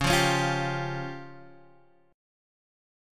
C#Mb5 chord